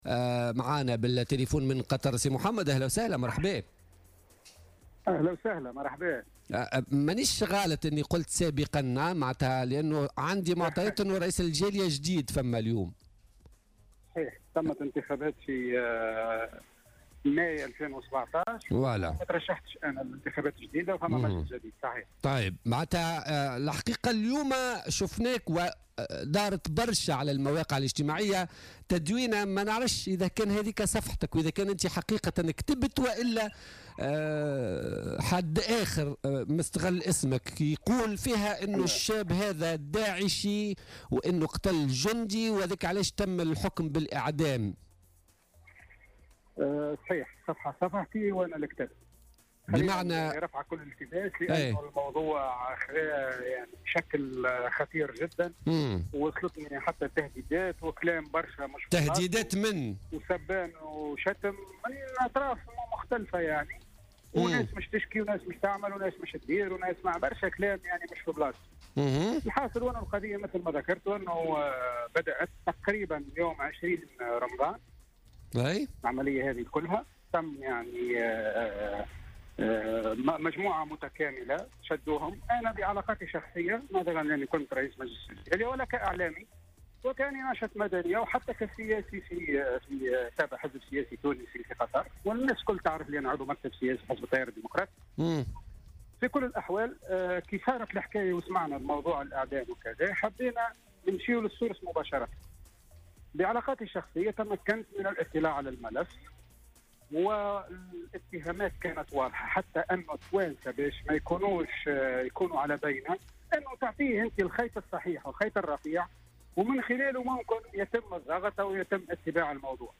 مداخلة هاتفية في برنامج "بوليتيكا"